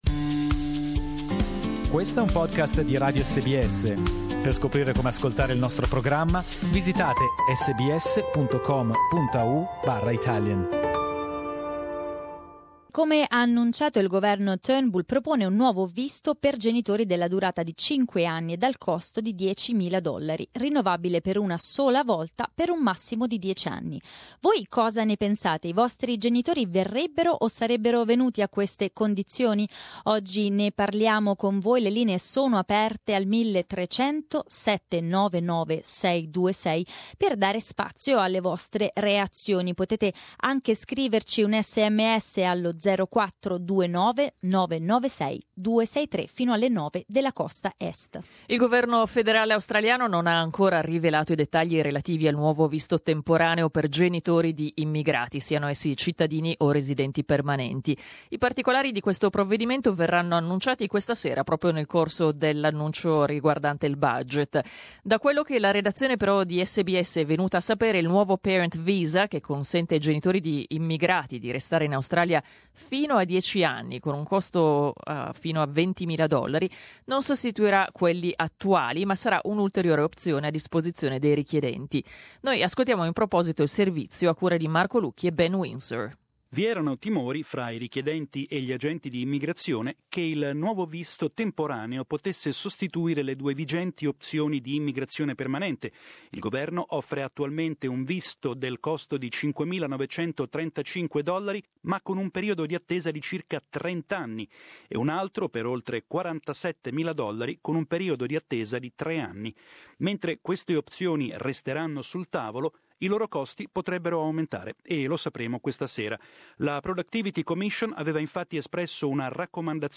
Under a new visa, parents could stay for up to 10 years but would never be allowed to settle in Australia, and their children would need to pay for private health cover. We talked about it with our listeners.